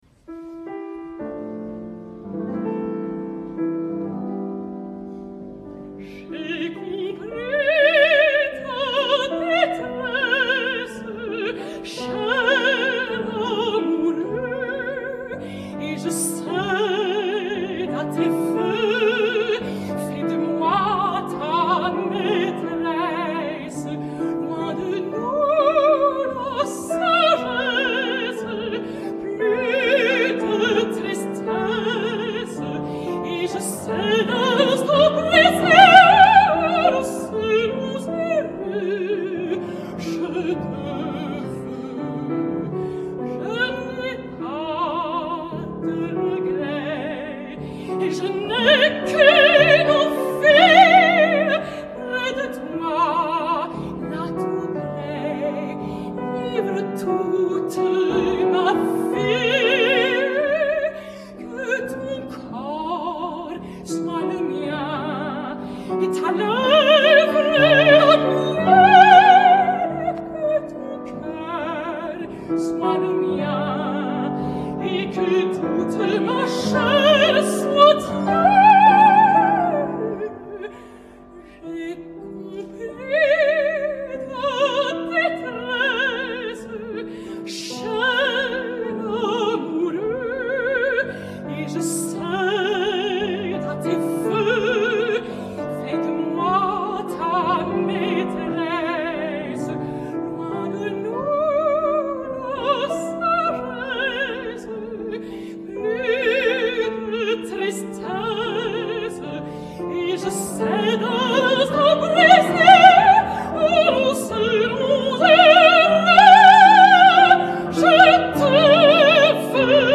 La mezzo soprano francesa
tal com la va cantar el passat 11 de febrer al Théâtre Marigny de Paris
piano